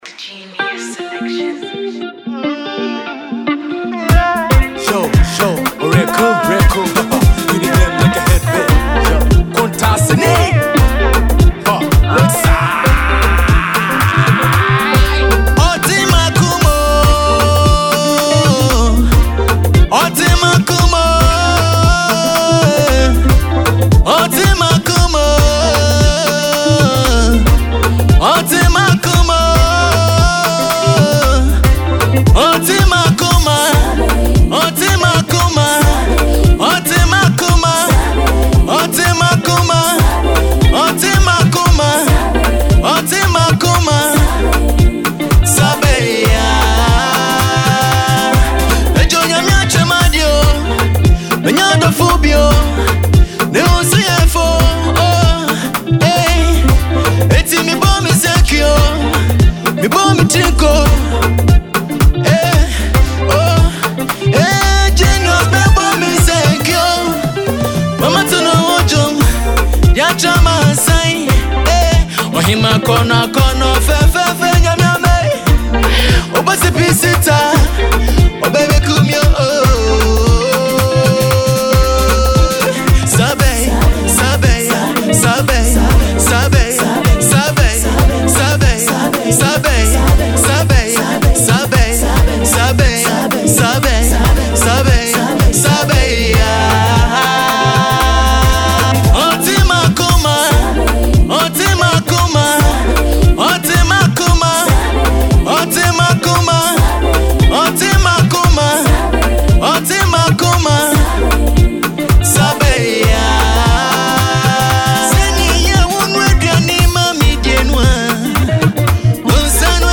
jamming Highlife song